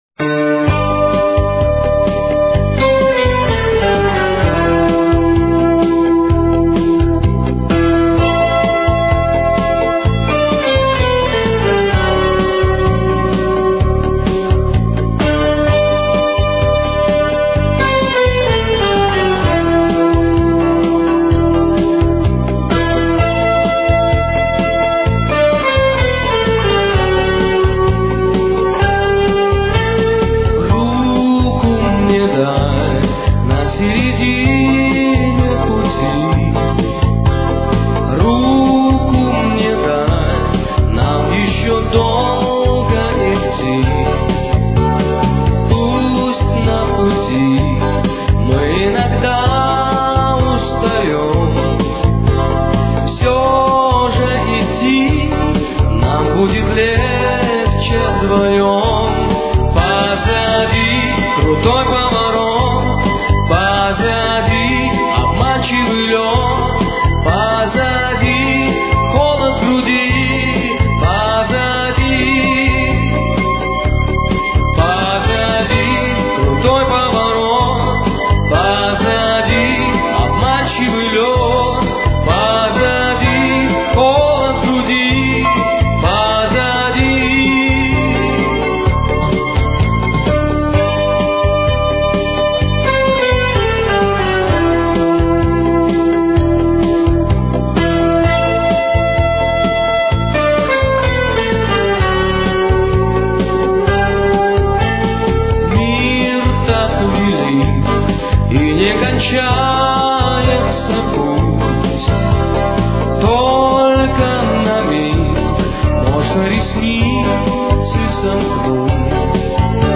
советский и российский поп-рок-певец, композитор и поэт.
Темп: 131.